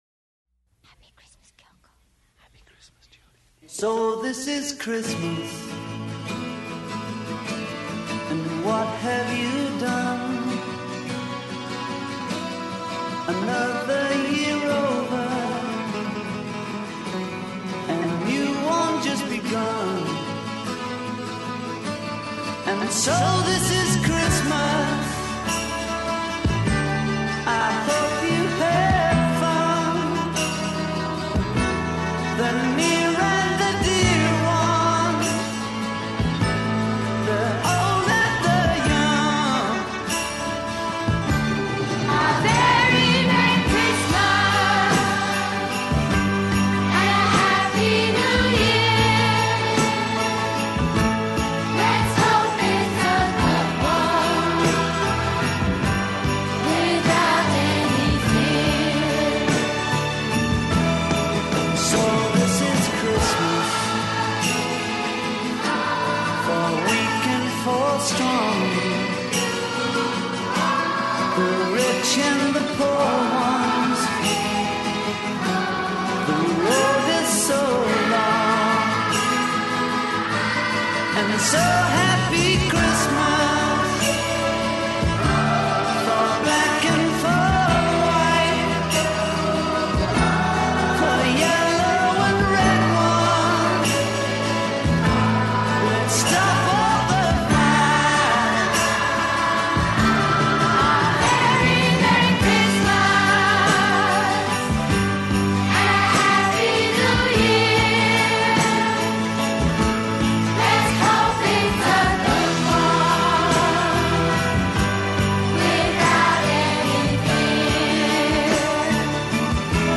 Plus they have some good conversation about the return of wholesome values, the lack of good rock & roll in Christmas music, and Lou Rawls’ career arc.